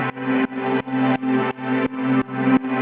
Index of /musicradar/sidechained-samples/170bpm
GnS_Pad-dbx1:4_170-C.wav